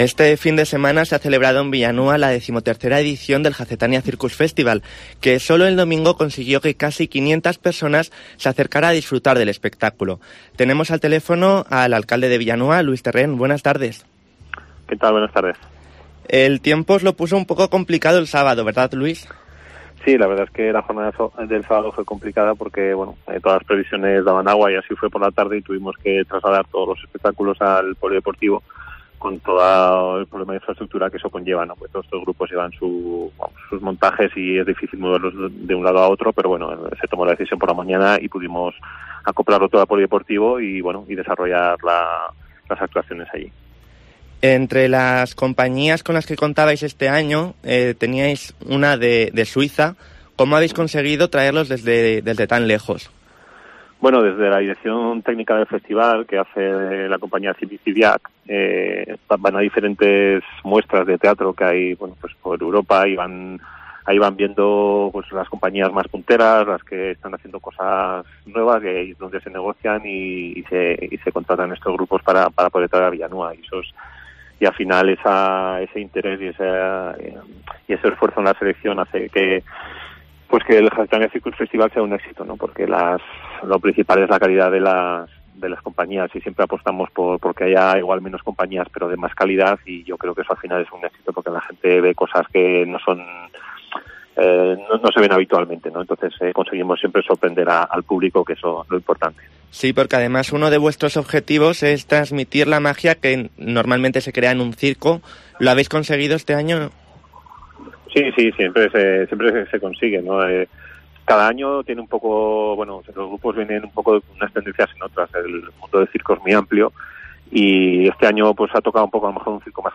Luís Terrén, alcalde de Villanúa, analiza en COPE el Jacetania Circus Festival